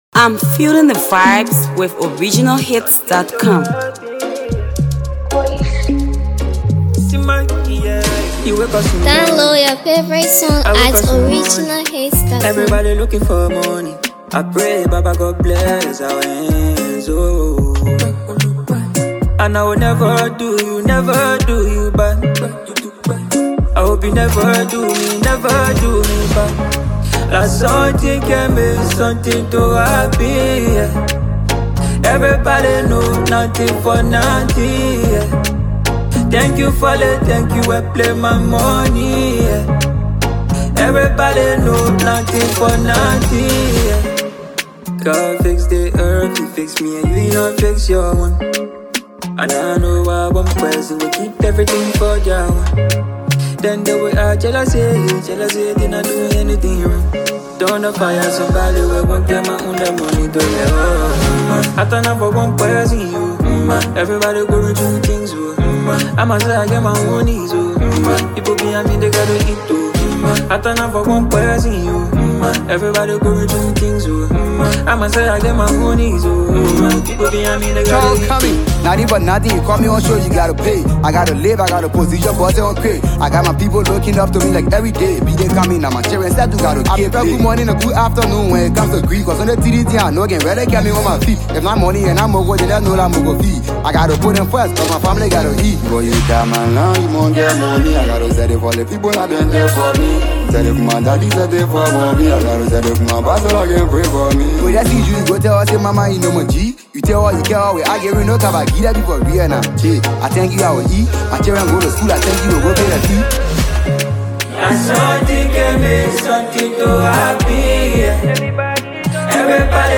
Hipco
His latest studio effort